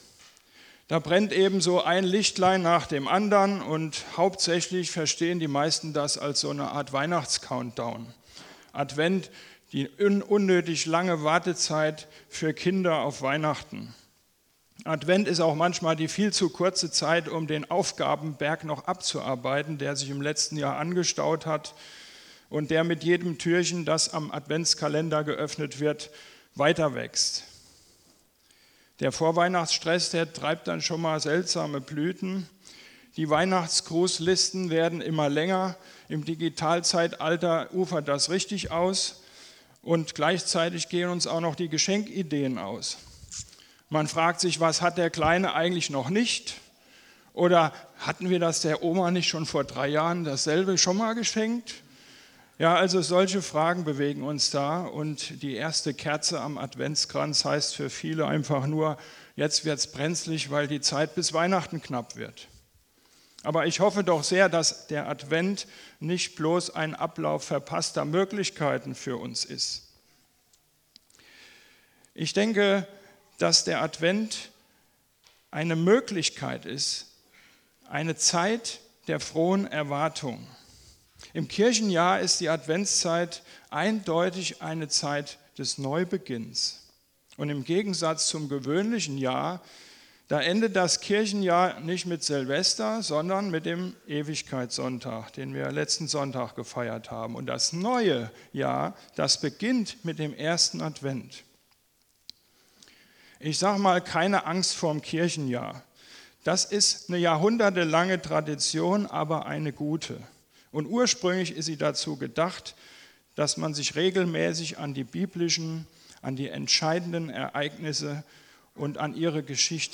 PREDIGTEN - Ev.